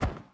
ItemDelete.wav